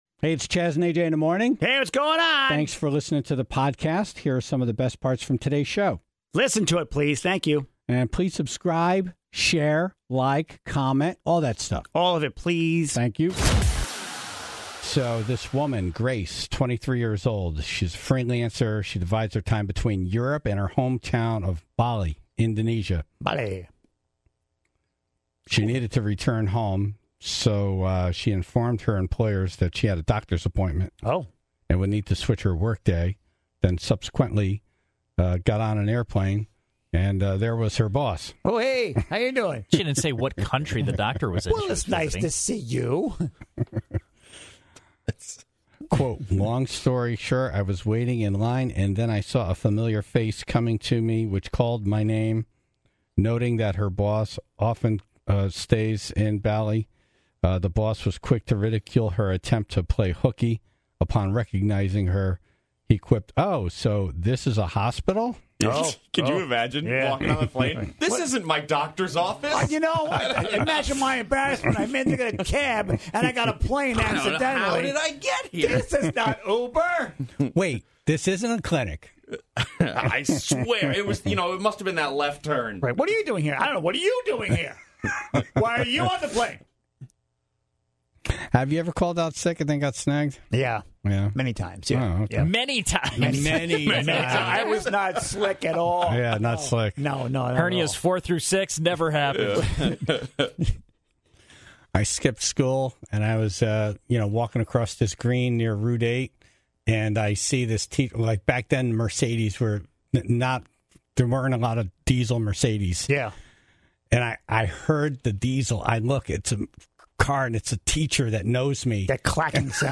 (2:58) Dumb Ass News - A woman was caught shoplifting from the same store, more than 30 times. The Tribe called in the single most expensive item they ever stole.